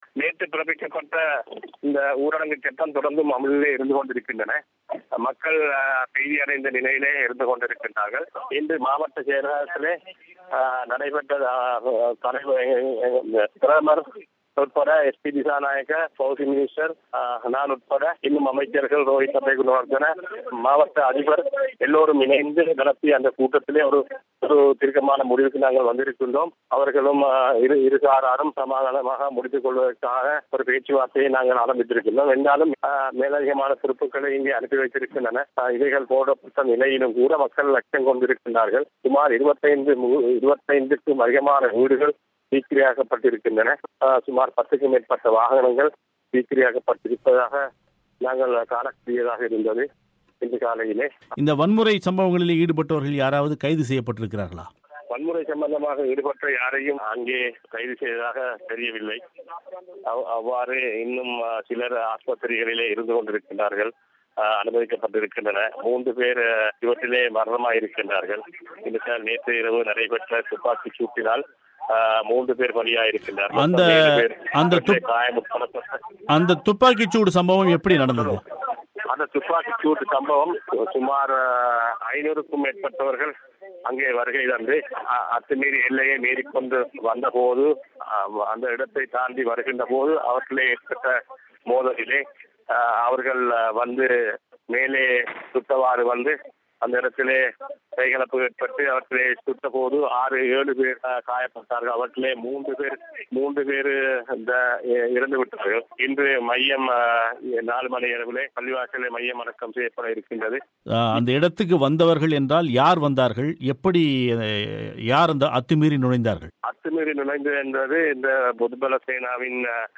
அளுத்கம நிகழ்வுகள் குறித்து களுத்துறை நாடாளுமன்ற உறுப்பினரான அஸ்லம் முஹமட் சலீம் அவர்களின் செவ்வி.